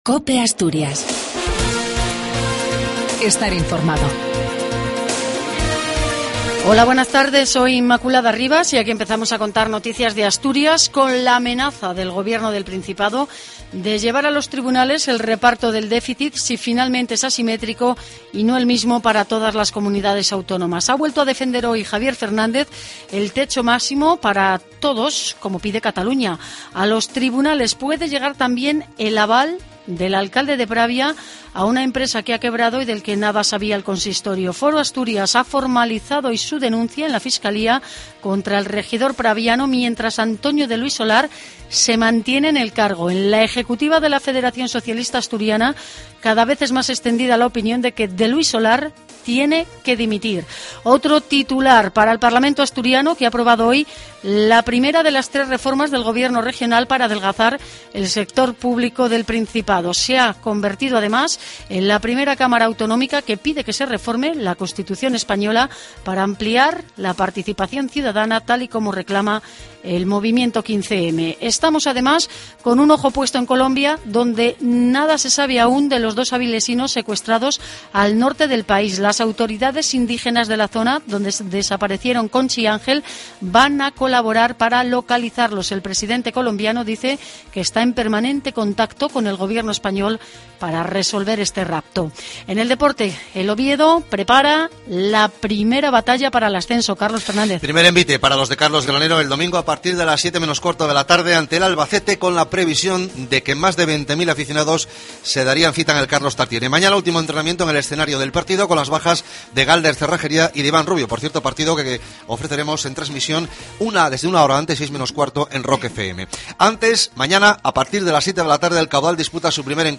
AUDIO: LAS NOTICIAS DE ASTURIAS AL MEDIODIA.